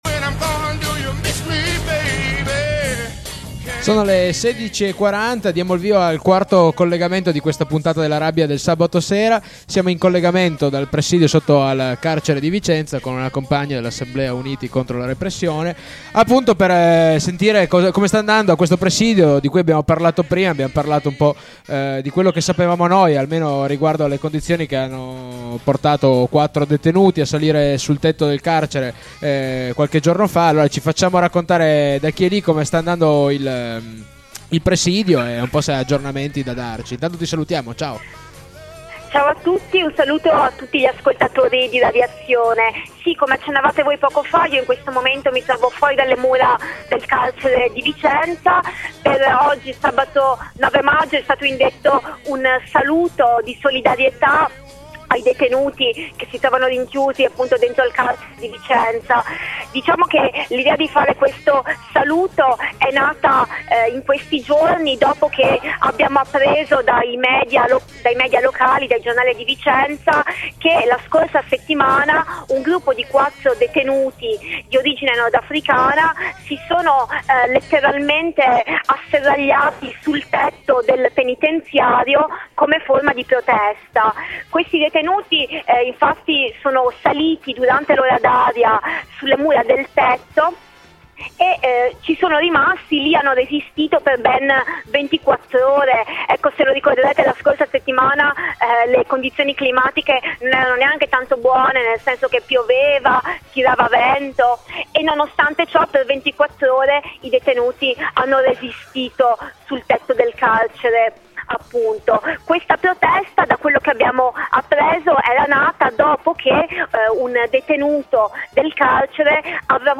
A una settimana dall’accaduto ci colleghiamo con il presidio, lanciato dall’Assemblea Uniti Contro la Repressione, sotto alle mura del carcere.